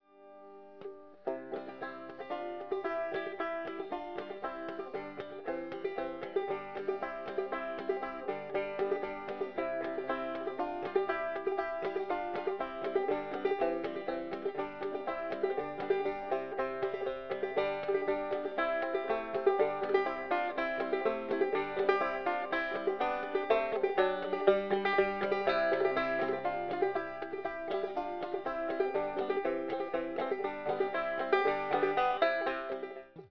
banjo, whistling, voice, 12-string guitar
soprano sax
triangle, bass drum
chorus